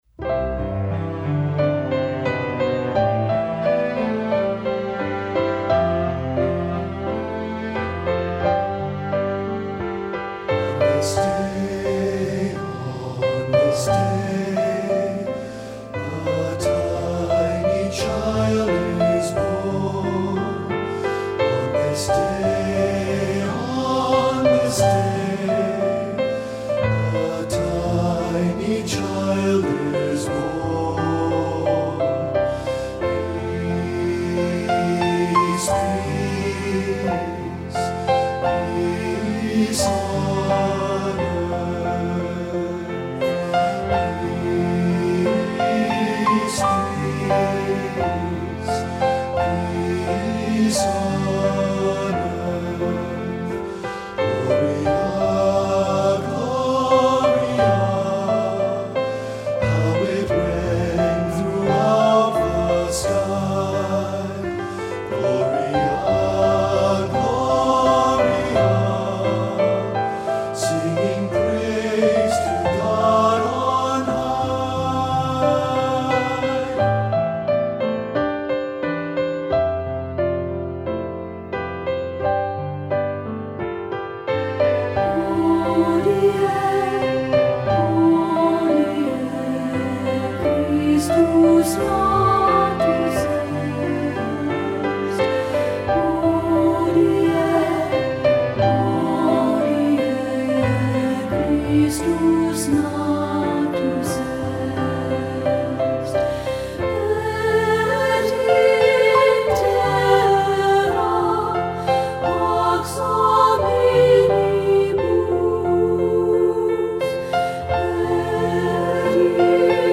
Voicing: SSAB